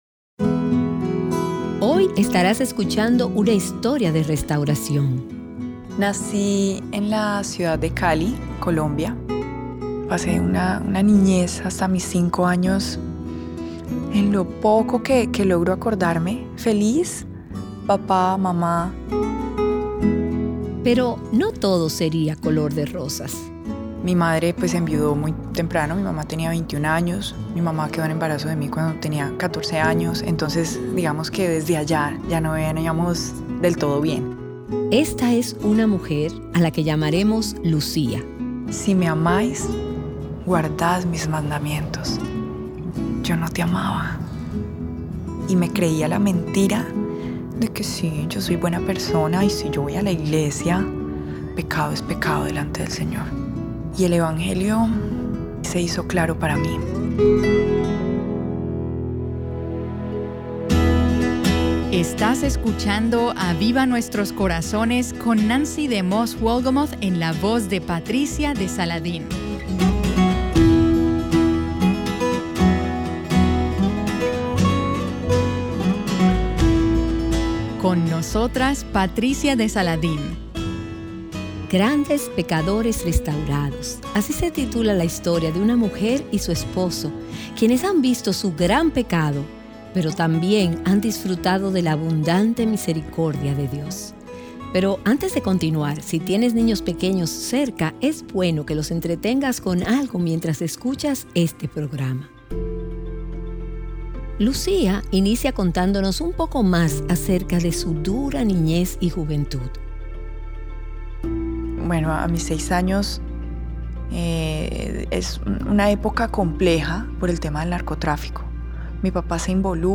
¿Has visto Su abundante misericordia en tu vida a pesar de tu pecado? Te invitamos a escuchar el testimonio de hoy.